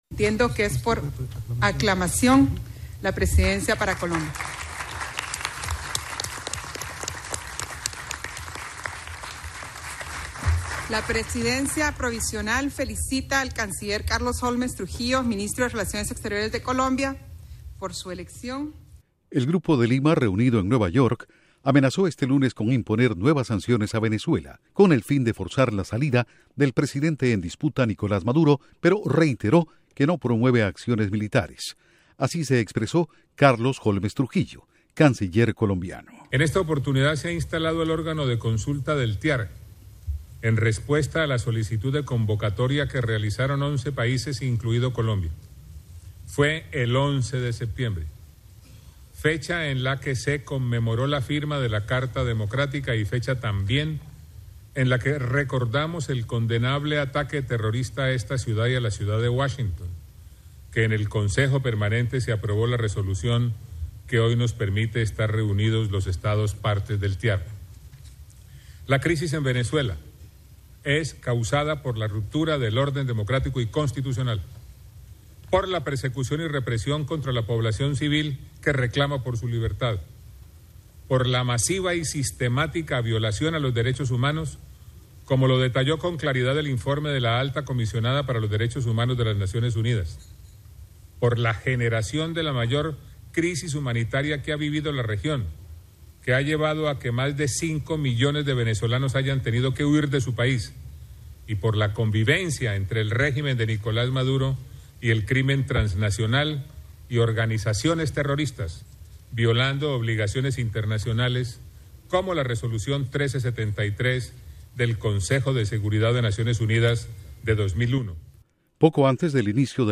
Duración: 2.36 Con audios de reunión